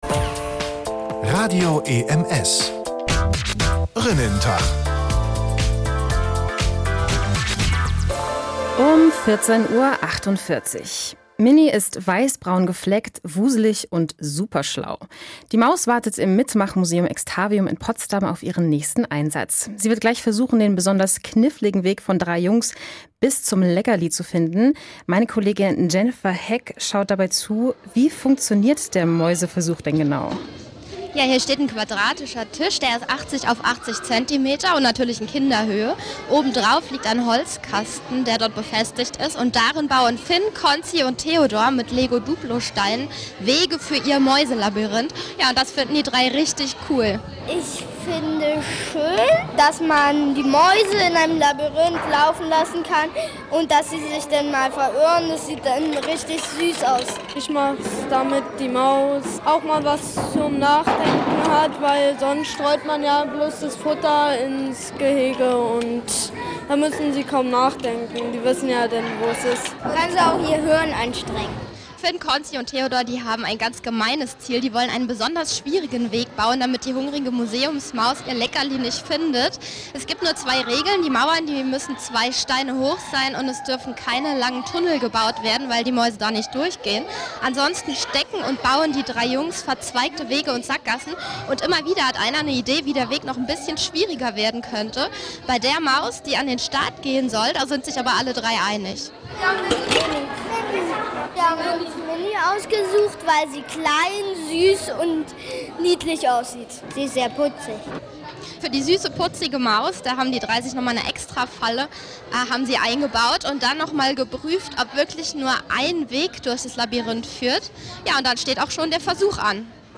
mit Einspielern
alles live, auch die Kinder